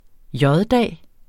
Udtale [ ˈjʌðˌdæˀ ]